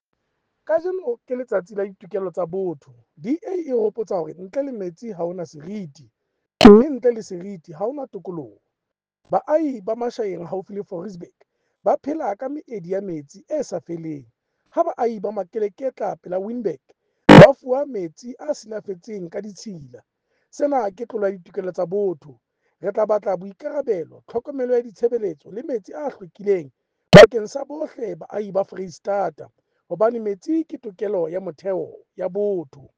Sesotho soundbite by Cllr Kabelo Moreeng